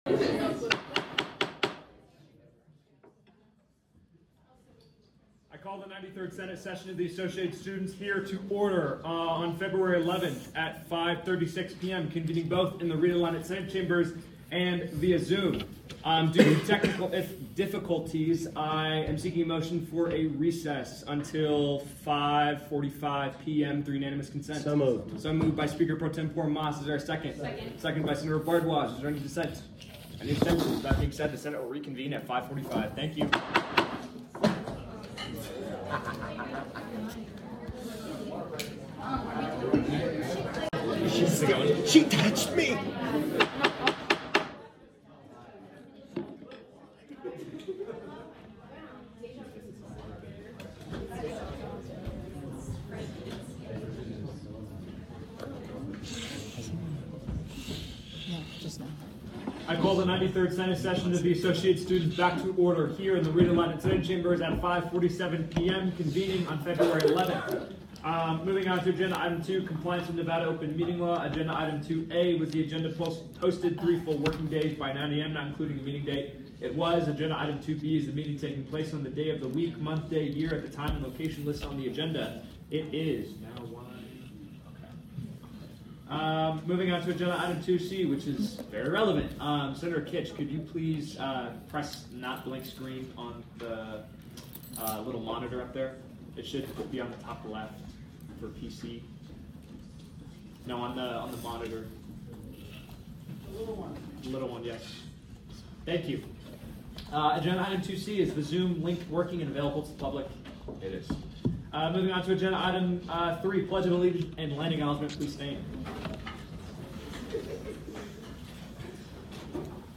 Meeting Type : Senate
Location : Rita Laden Senate Chambers